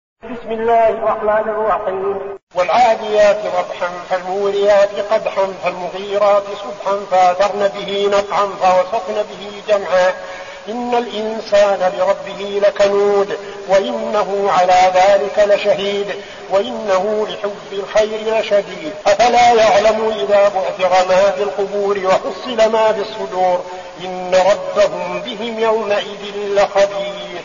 المكان: المسجد النبوي الشيخ: فضيلة الشيخ عبدالعزيز بن صالح فضيلة الشيخ عبدالعزيز بن صالح العاديات